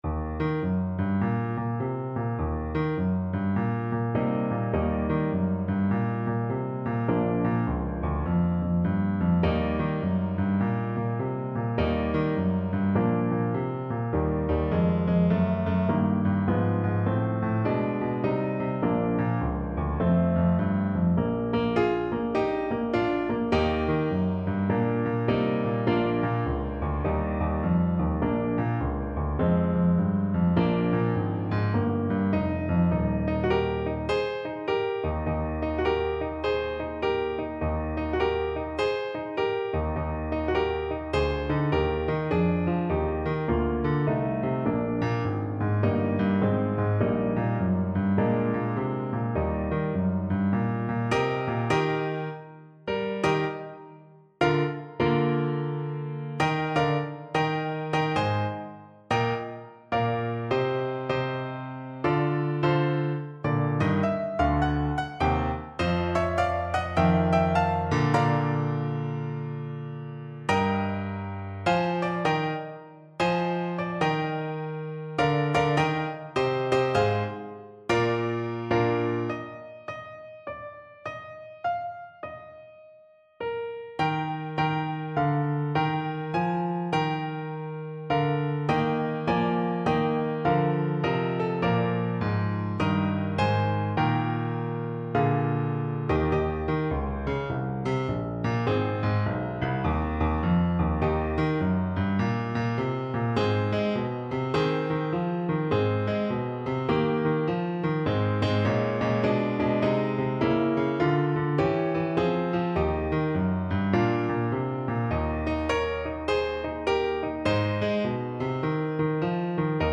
With a swing! =c.140